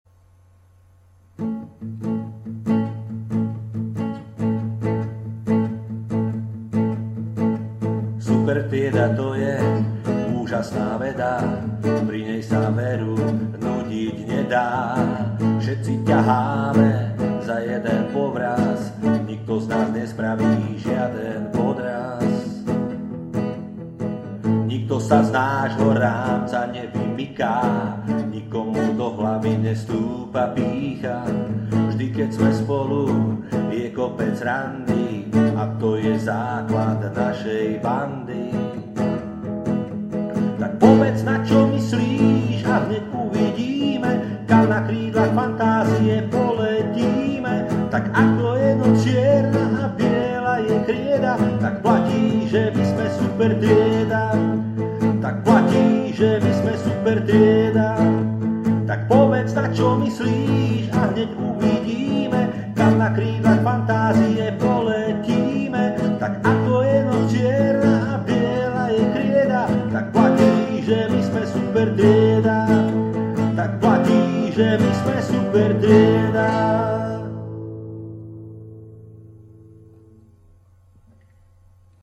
pozrite si a vypočujte pieseň o Supertriede, ktorú vymysleli deti z Michaloviec.